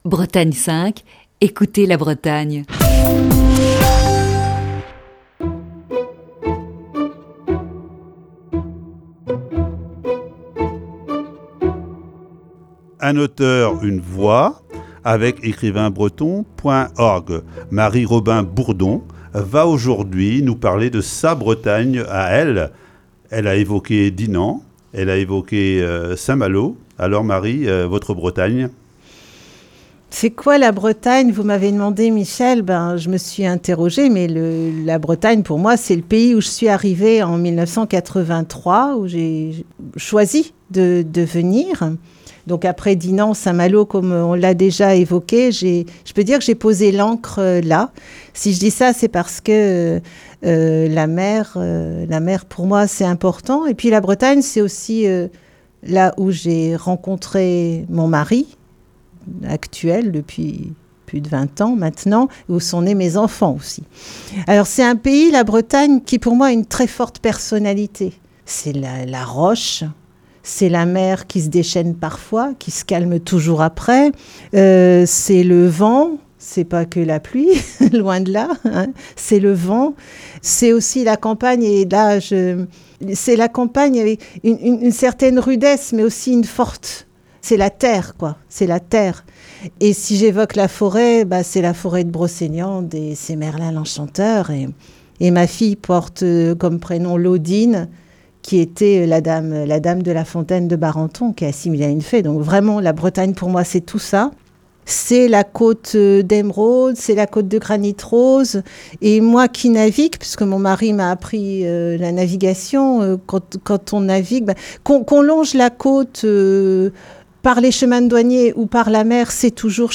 Ce matin, deuxième partie de cet entretien.